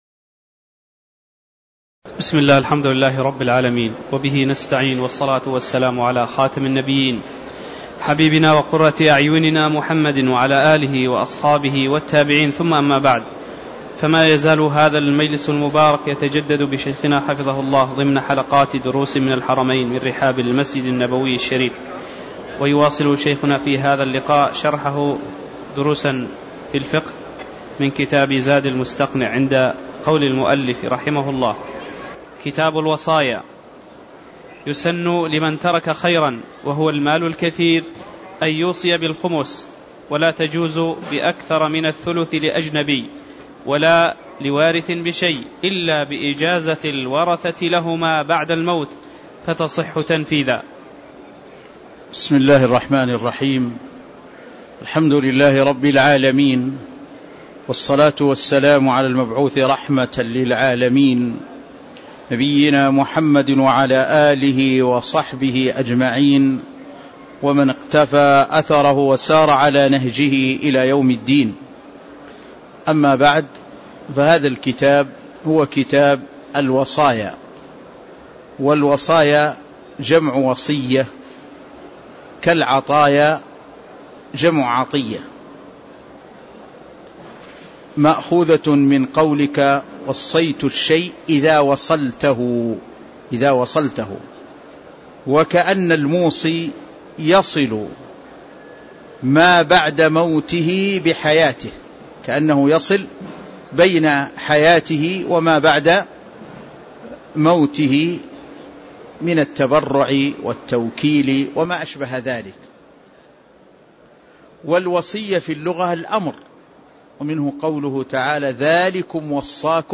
تاريخ النشر ١٤ ربيع الثاني ١٤٣٧ هـ المكان: المسجد النبوي الشيخ